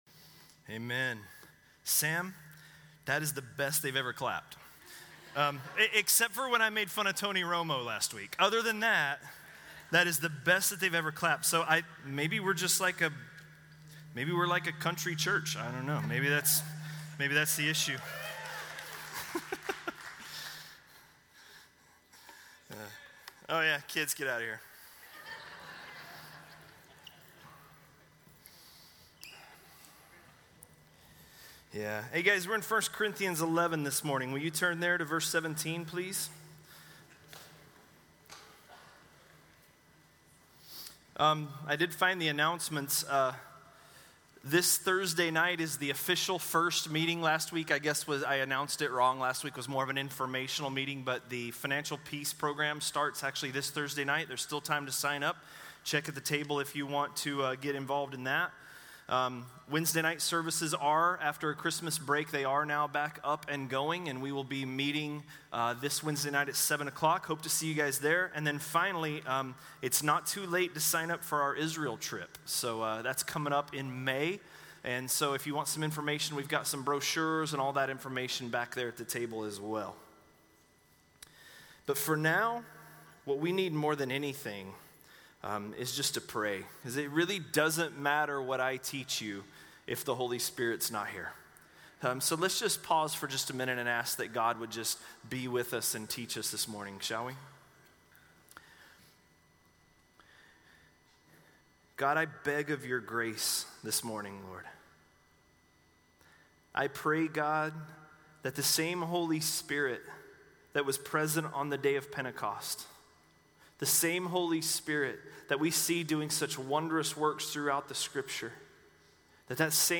A message from the series "1 Corinthians." 1 Corinthians 11:17–11:34